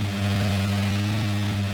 boatengine_revhighloop.wav